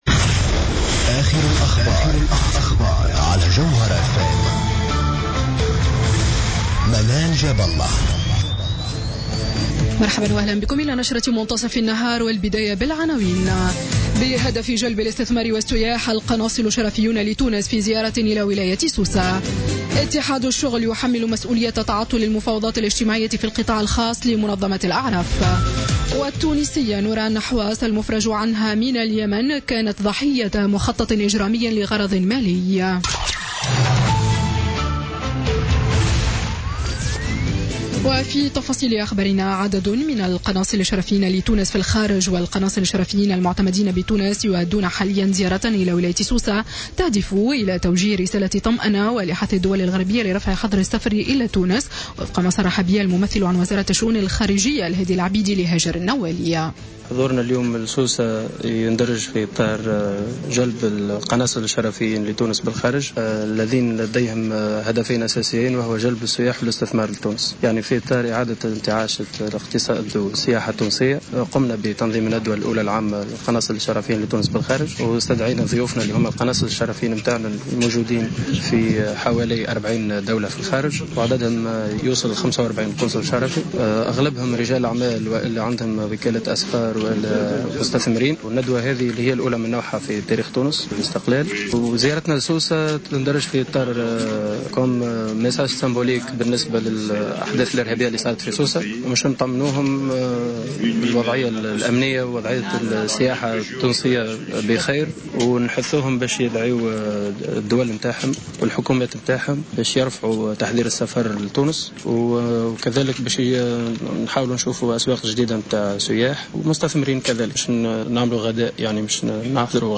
نشرة أخبار منتصف النهار ليوم الثلاثاء 4 أكتوبر 2016